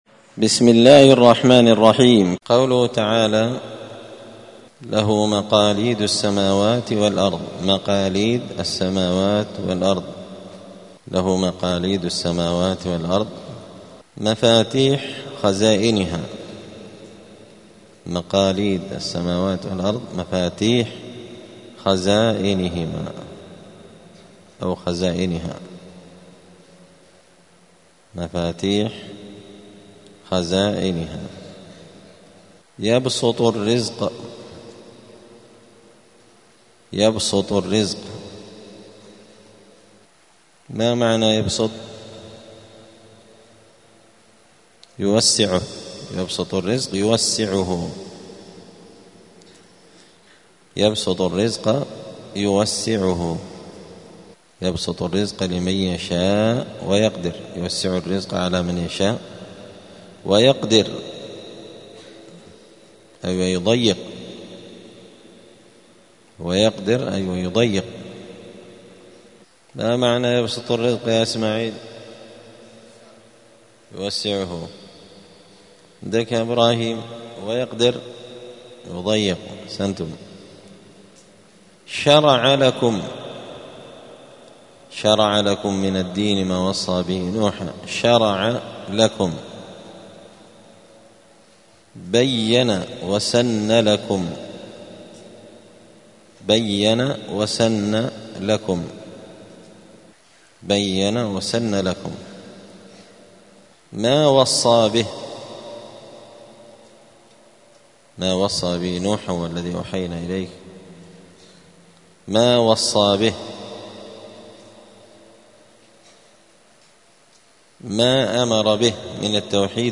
الأحد 14 جمادى الآخرة 1446 هــــ | الدروس، دروس القران وعلومة، زبدة الأقوال في غريب كلام المتعال | شارك بتعليقك | 31 المشاهدات
دار الحديث السلفية بمسجد الفرقان قشن المهرة اليمن